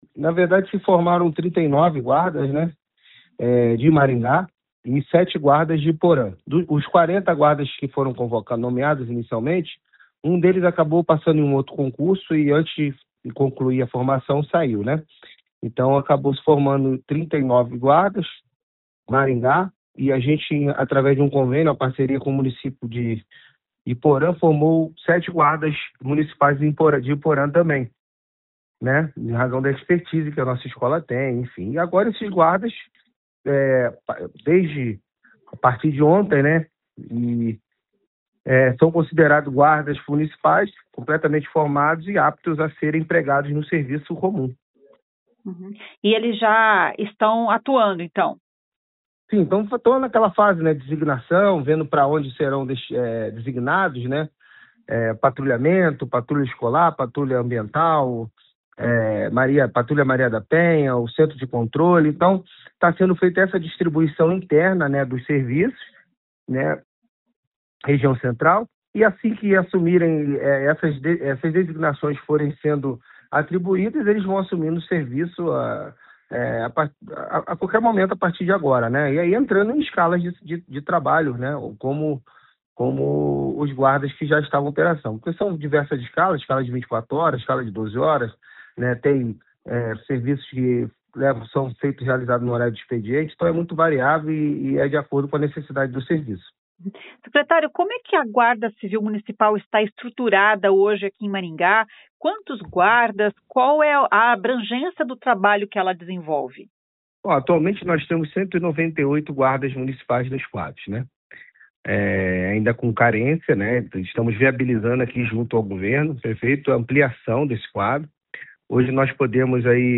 O objetivo é combater o tráfico de drogas na região. A GCM ganhou o reforço dos novos agentes que se formaram esta semana. 39 novos agentes vão atuar na Guarda Civil Municipal de Maringá, explica o secretário Luiz Alves.